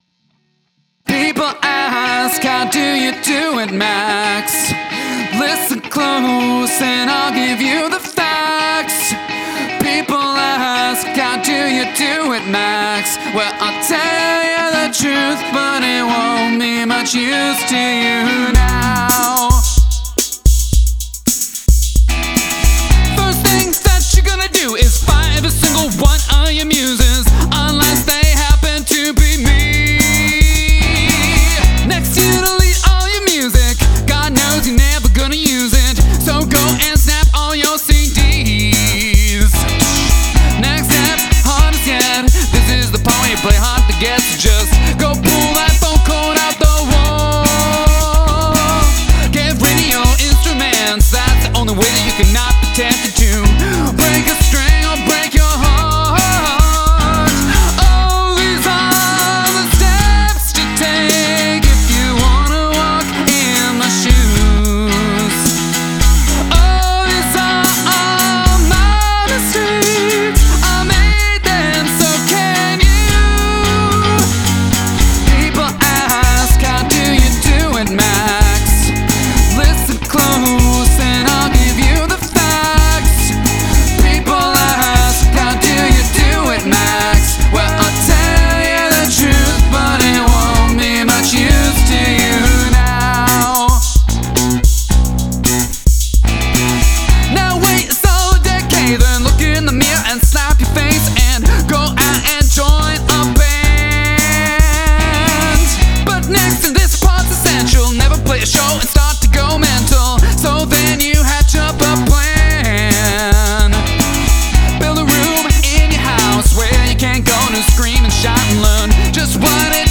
I like the little stabs of high funky guitar.
Nice harmonies, nice melodies.